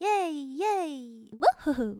欢呼.wav
欢呼.wav 0:00.00 0:02.00 欢呼.wav WAV · 172 KB · 單聲道 (1ch) 下载文件 本站所有音效均采用 CC0 授权 ，可免费用于商业与个人项目，无需署名。
人声采集素材/人物休闲/欢呼.wav